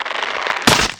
vine.ogg